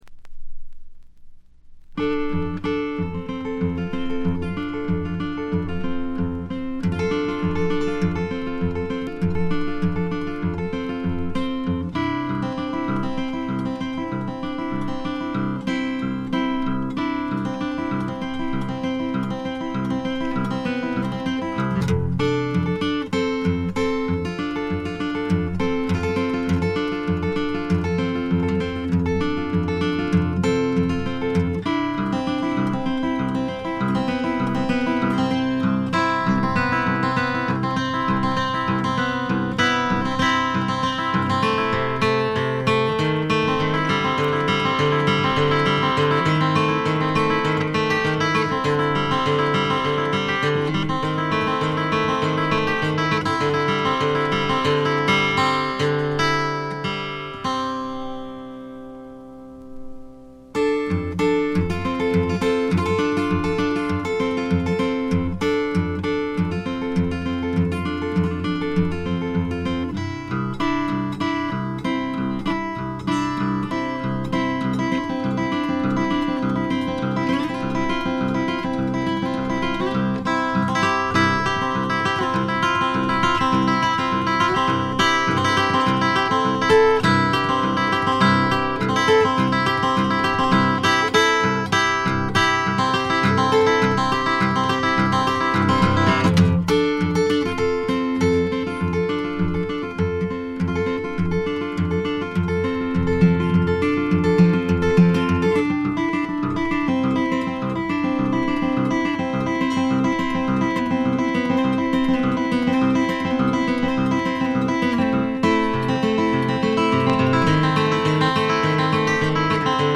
部分試聴ですが軽微なチリプチ少し。
サイケ、アシッドに接近した名作。
試聴曲は現品からの取り込み音源です。